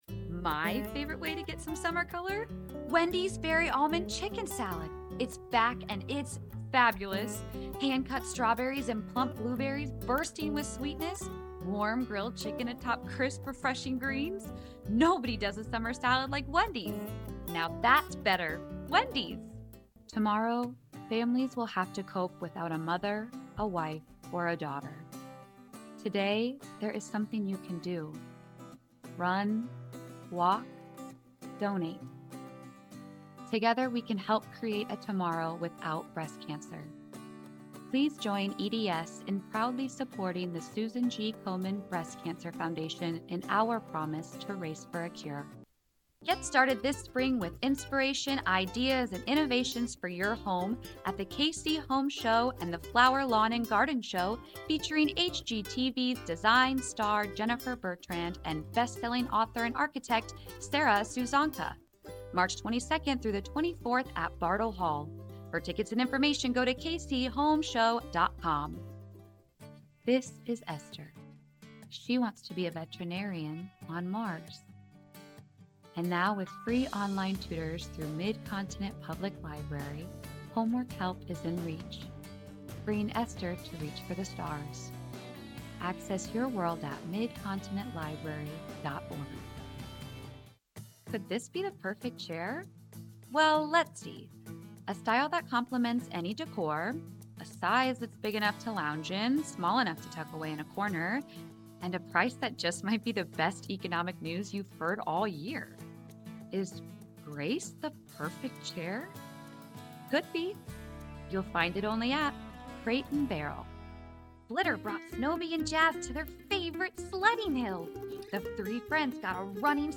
Gender: Female
VOICE OVER DEMO